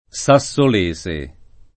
vai all'elenco alfabetico delle voci ingrandisci il carattere 100% rimpicciolisci il carattere stampa invia tramite posta elettronica codividi su Facebook sassolese [ S a SS ol %S e ] o sassolino [ S a SS ol & no ] etn. (di Sassuolo)